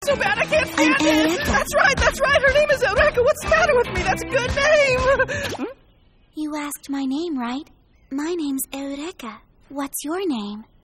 うれしいので、発表された声優さんが他の作品で演じた声を聴きながらどんな感じになるのか想像してみました。